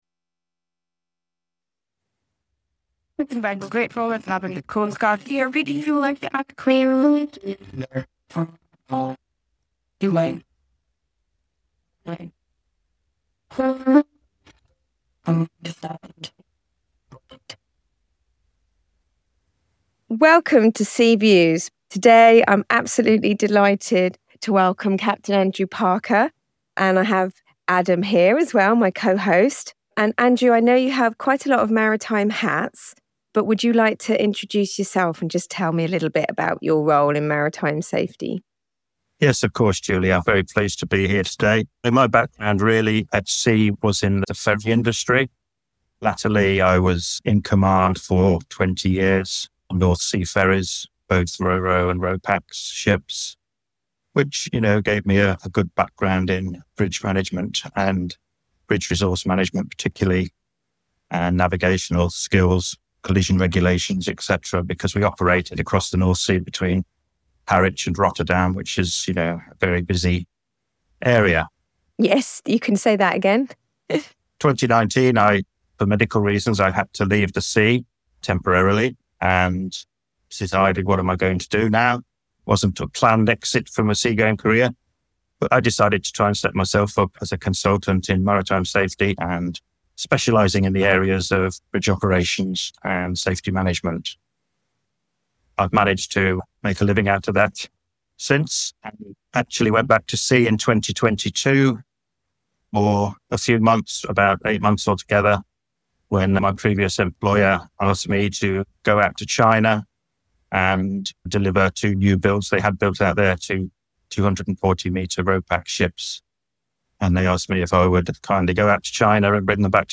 He unpacks the impact of human factors, discusses the limitations of current training systems, and advocates for the accreditation of virtual reality as a transformative tool in maritime education. This is a candid conversation about what it really takes to run a safe and effective bridge — and why the future of safety lies in both people and innovation.